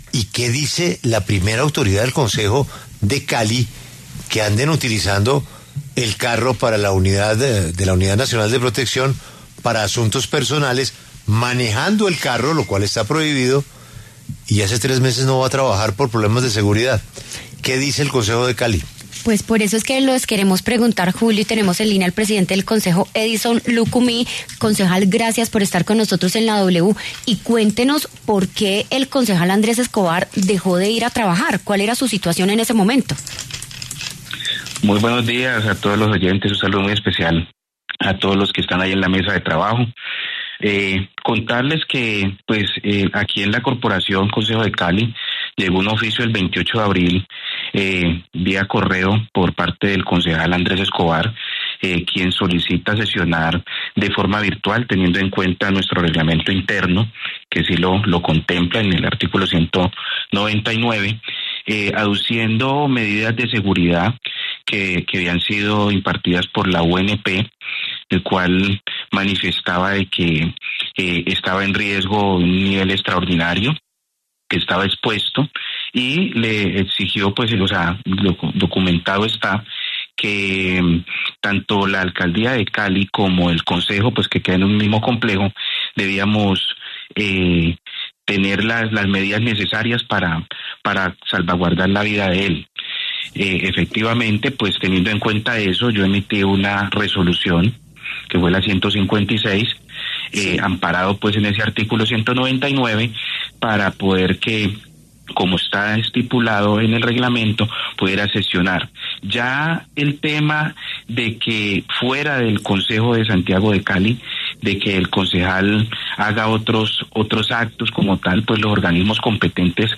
En La W, el presidente del Concejo Distrital de Cali, Edison Lucumí, se pronunció en La W sobre la denuncia contra el concejal Andrés Escobar por haber sido visto conduciendo una camioneta oficial para asistir a un restaurante.